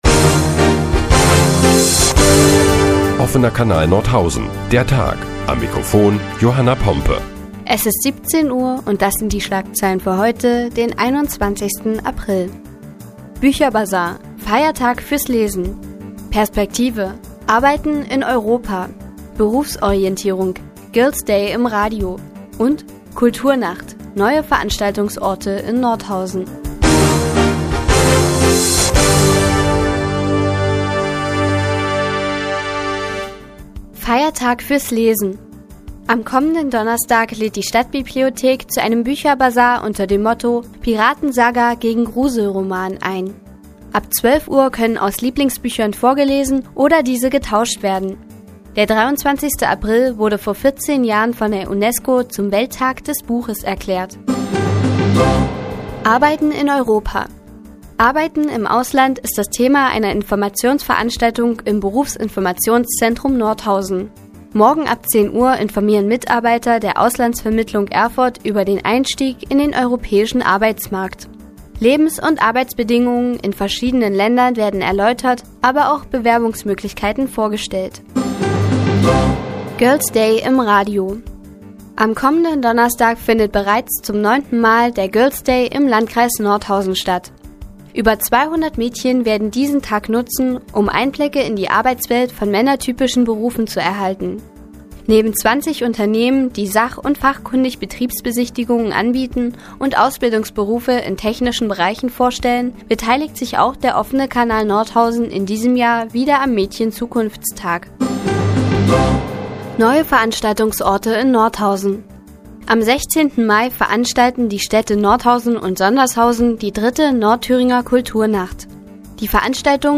Die tägliche Nachrichtensendung des OKN ist nun auch in der nnz zu hören. Heute geht es um den "Welttag des Buches" und den bevorstehenden Mädchen-Zukunfts-Tag.